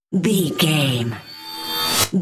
Riser sci fi electronic flashback
Sound Effects
Atonal
futuristic
intense
tension